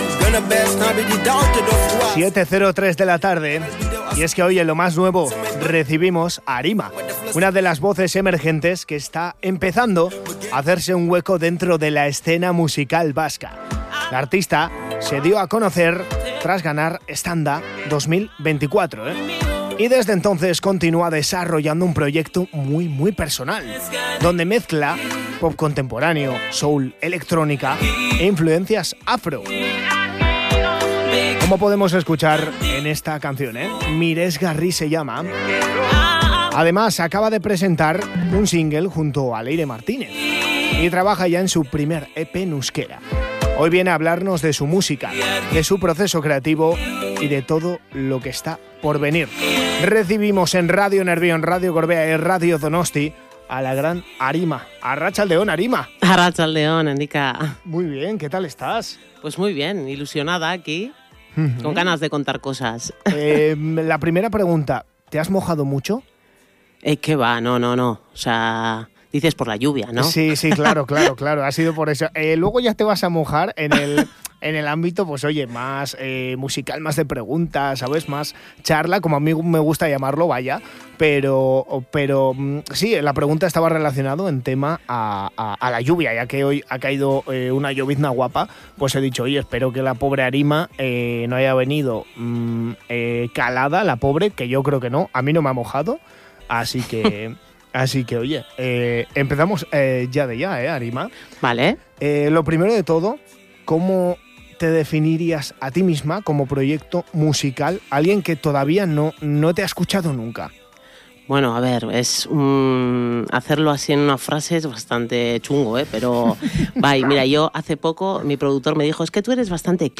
La cantante vizcaína visita los estudios de la Gran Familia y deja entrever que se encuentra en su mejor momento